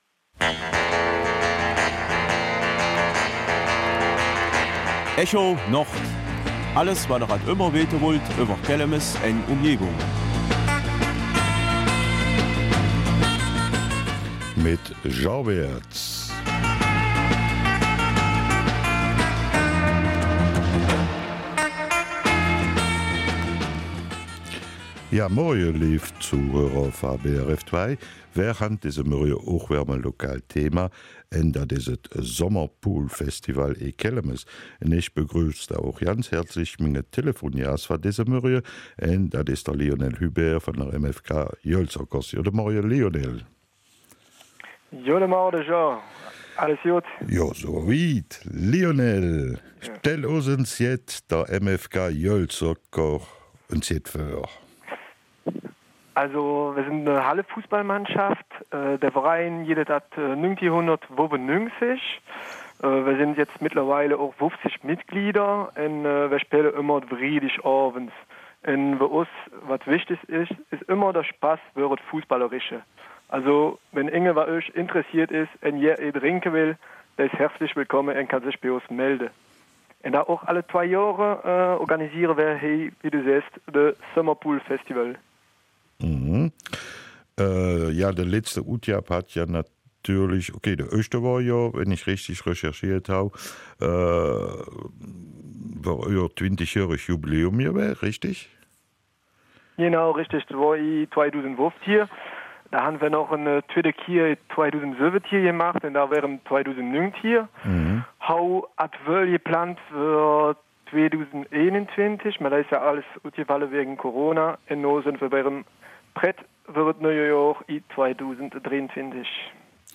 Kelmiser Mundart: Summer Pool Festival
Telefongast